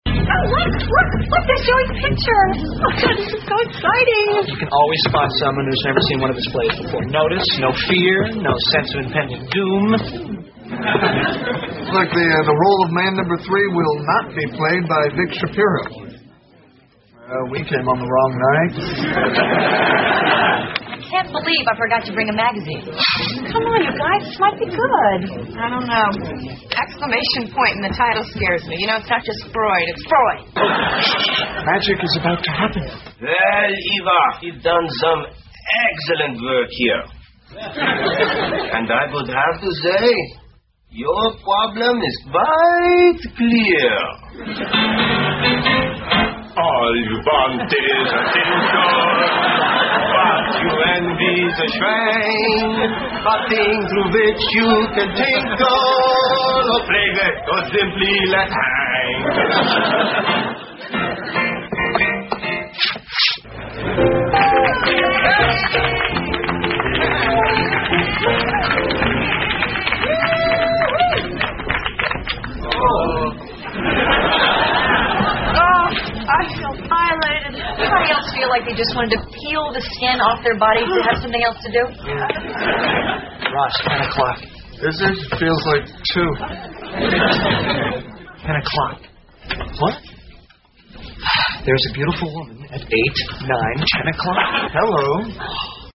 在线英语听力室老友记精校版第1季 第61期:屁股秀(1)的听力文件下载, 《老友记精校版》是美国乃至全世界最受欢迎的情景喜剧，一共拍摄了10季，以其幽默的对白和与现实生活的贴近吸引了无数的观众，精校版栏目搭配高音质音频与同步双语字幕，是练习提升英语听力水平，积累英语知识的好帮手。